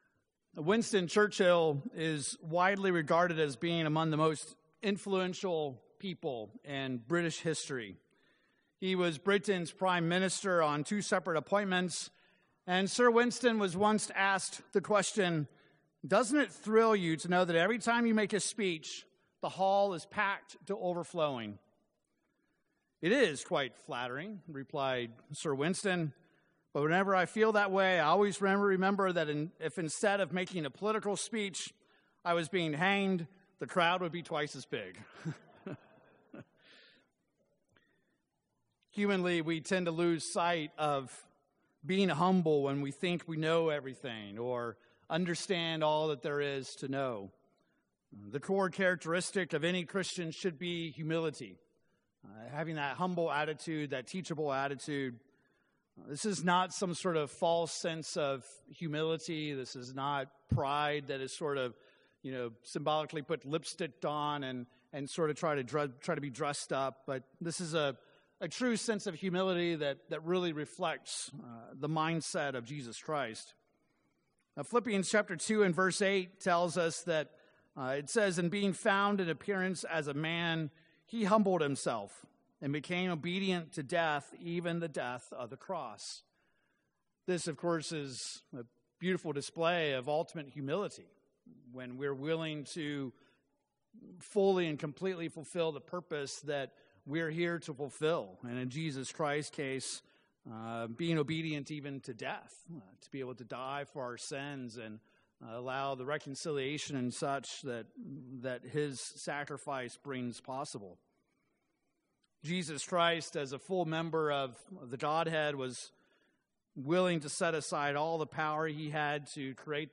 As the Passover approaches, this sermon focuses on the scriptures and meaning of the symbols for the Passover service. We will examine this Festival, its tremendous meaning, and the symbols observed in its observance.